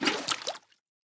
fill_axolotl3.ogg
Minecraft Version Minecraft Version snapshot Latest Release | Latest Snapshot snapshot / assets / minecraft / sounds / item / bucket / fill_axolotl3.ogg Compare With Compare With Latest Release | Latest Snapshot